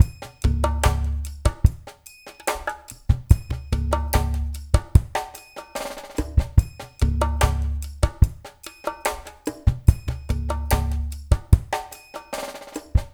BOL FULLDR-L.wav